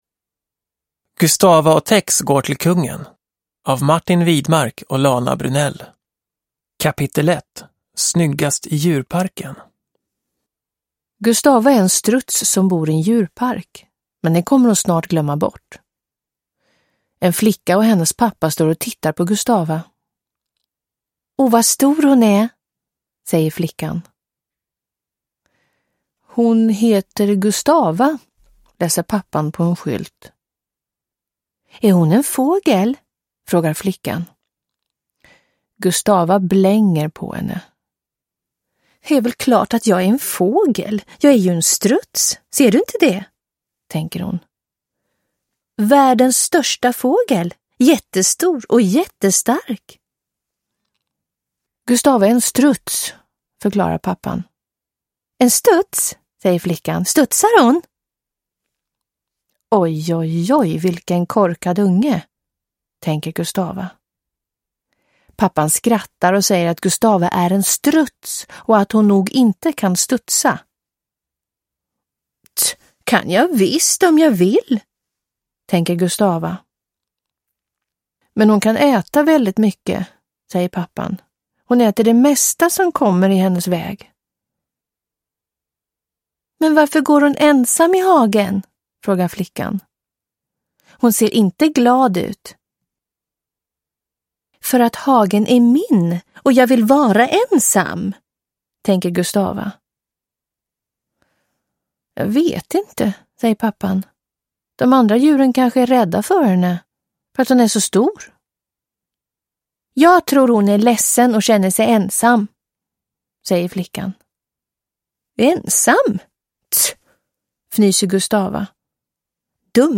Gustava & Tex går till kungen – Ljudbok – Laddas ner